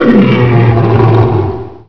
attack1long.wav